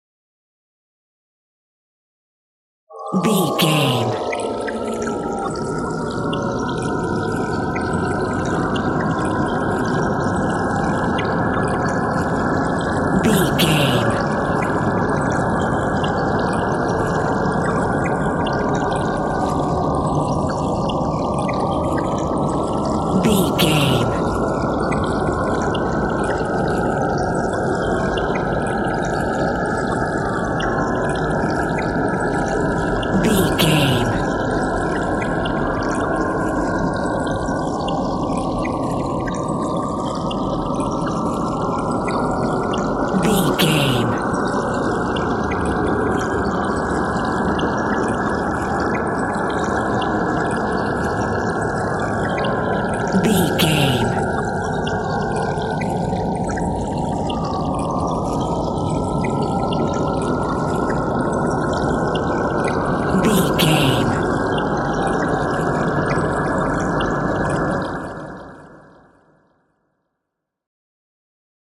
Evil scary soundscape pad
Sound Effects
Atonal
ominous
dark
haunting
eerie
ambience